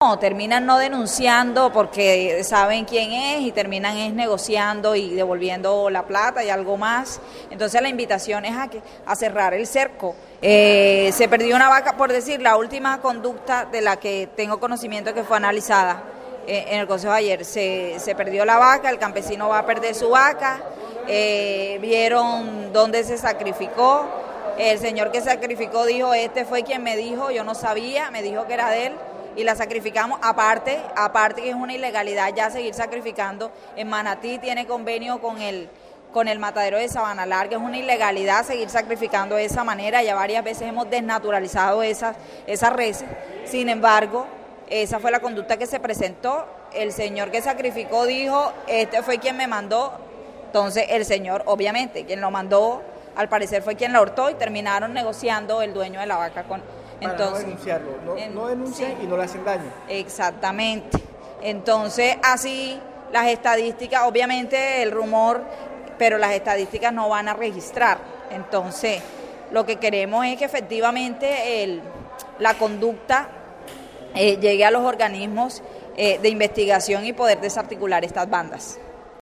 AUDIO-KELLY-PATERNINA-ALCALDESA-MANATI.mp3